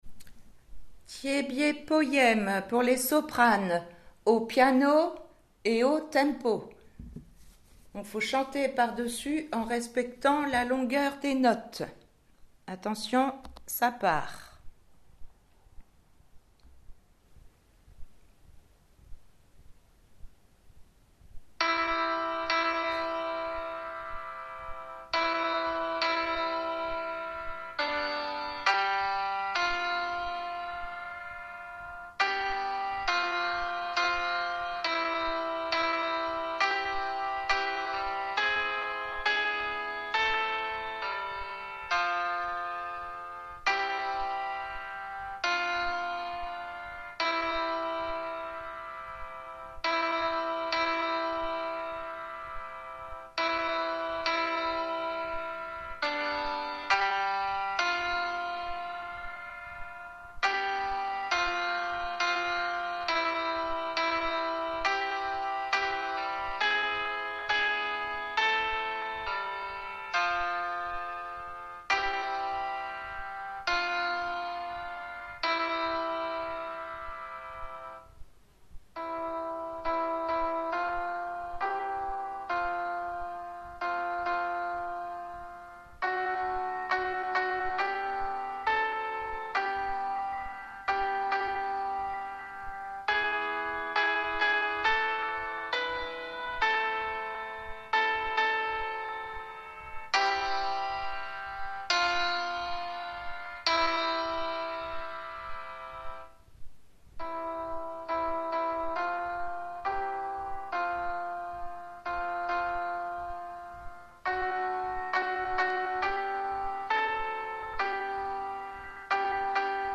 Tiébié piano Soprani